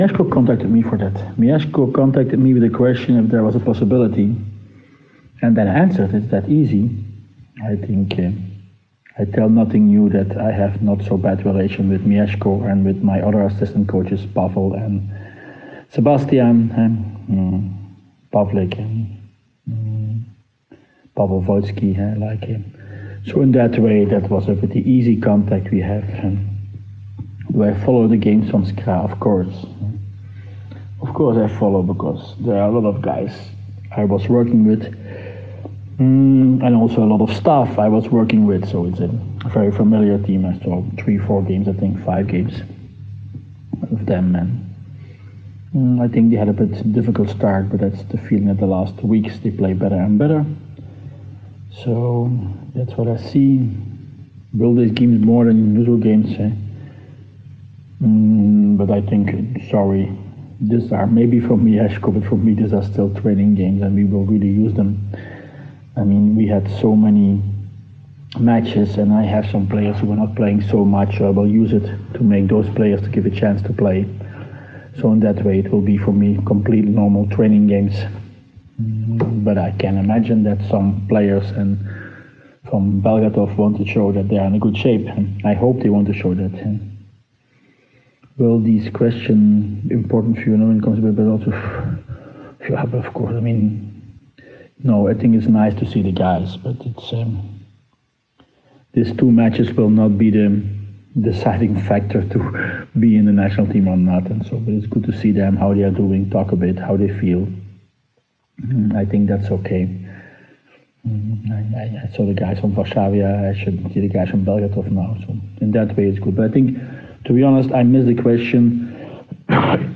Już 6 i 7 stycznia PGE Skra Bełchatów towarzysko zagra z Sir Safety Perugia. Poniżej wywiad z trenerem włoskiej drużyny oraz selekcjonerem reprezentacji Polski Vitalem Heynenem.